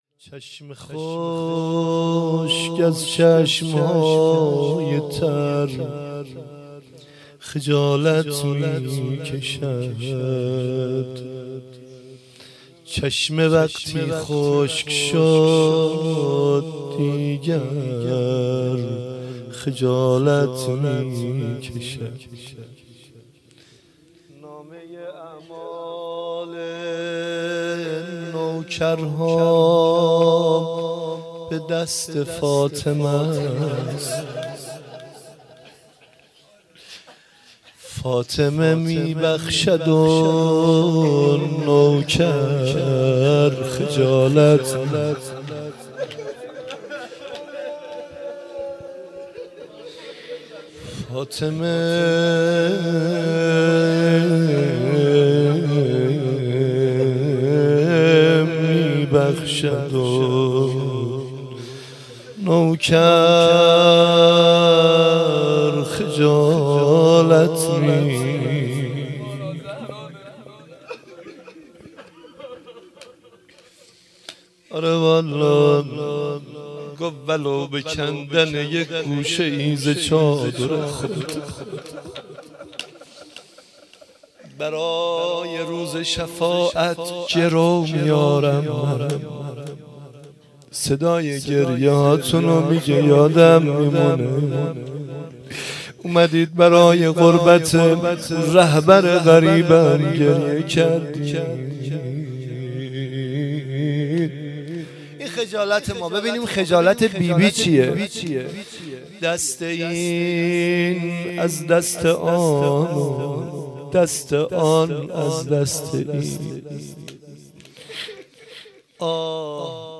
خیمه گاه - حسینیه بیت النبی - 95/12/08-وفات حضرت زهرا س - شعر خوانی